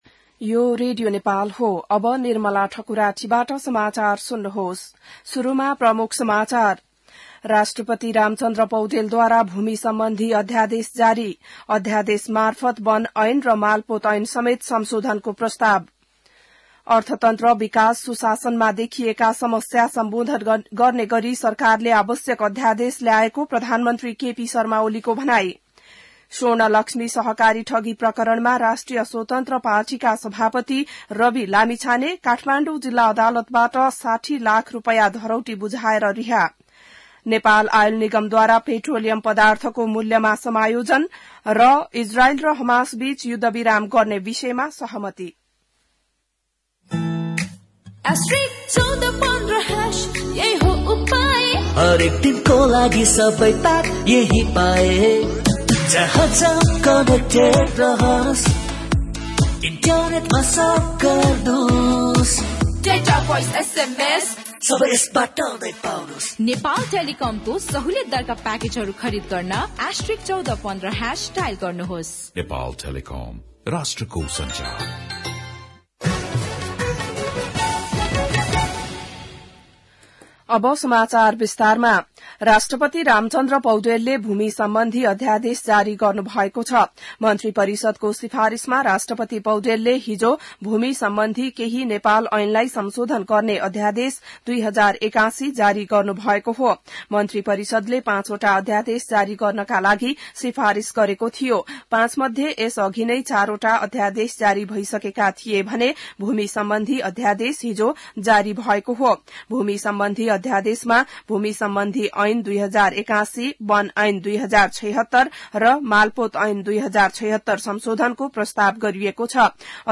बिहान ७ बजेको नेपाली समाचार : ४ माघ , २०८१